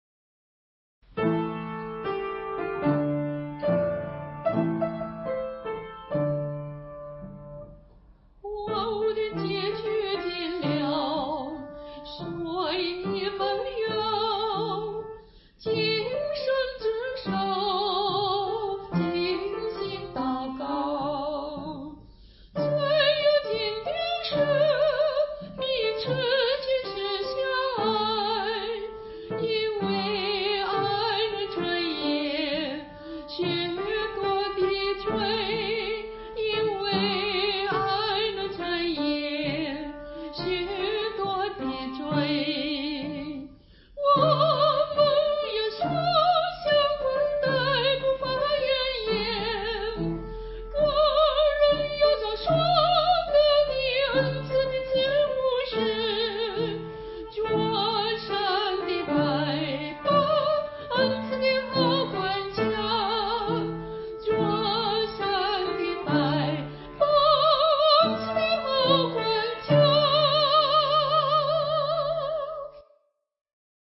导读：本颂赞诗歌歌谱采用2017年修订版，录音示范暂用旧版，将逐渐更新。
伴奏